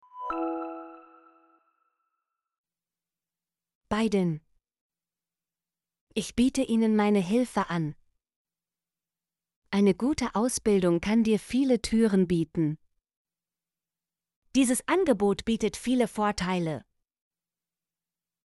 biden - Example Sentences & Pronunciation, German Frequency List